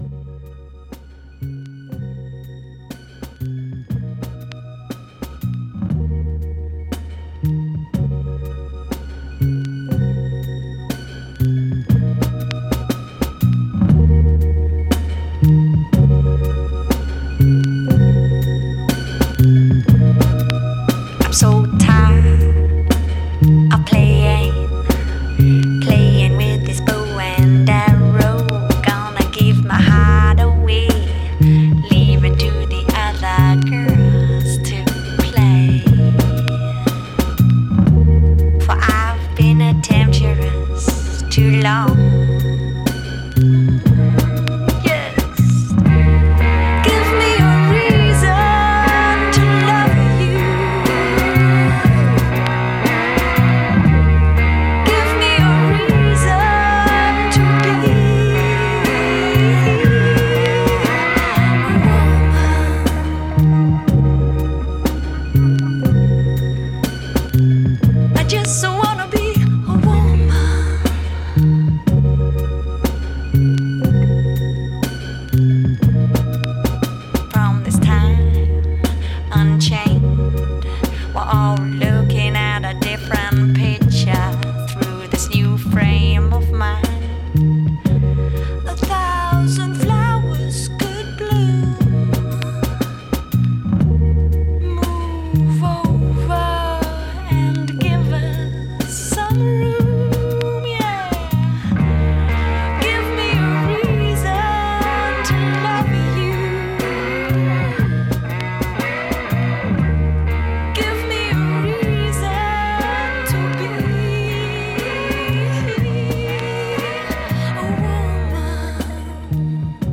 ominous sounding production
beautiful guitar work
ethereal, stunningly emotive vocals